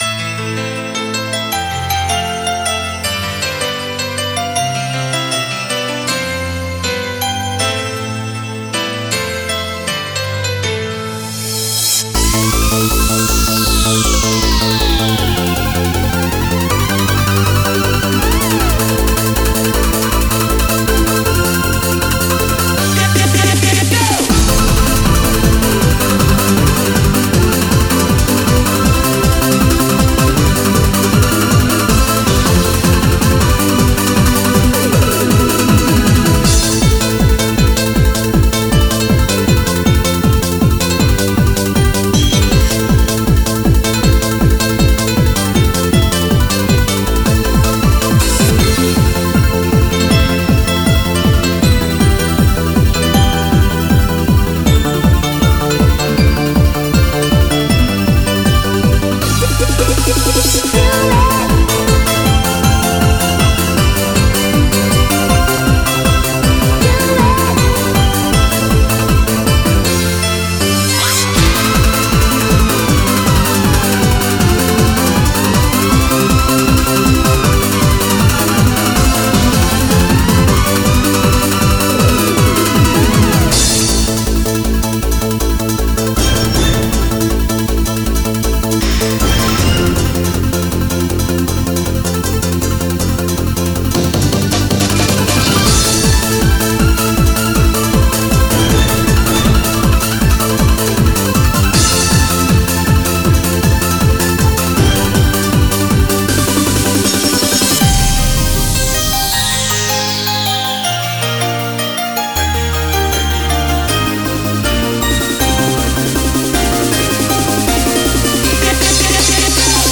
BPM158
Audio QualityPerfect (High Quality)